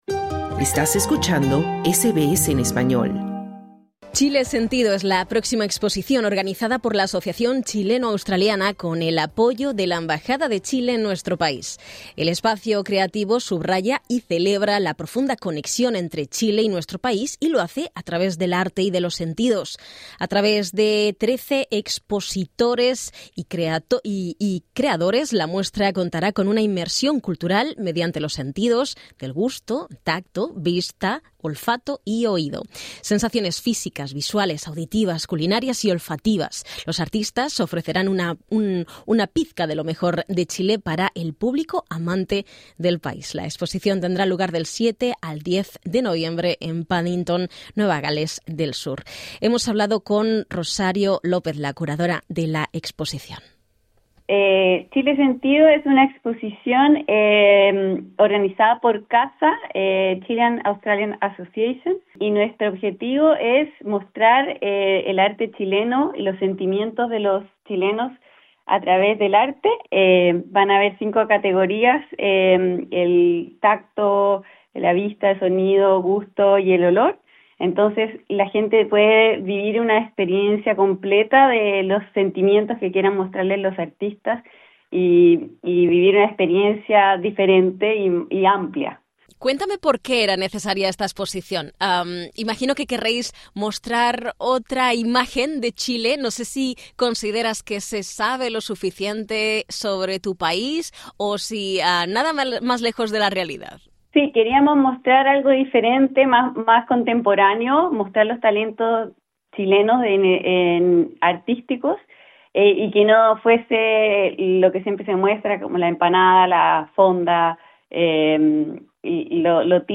SBS Spanish conversó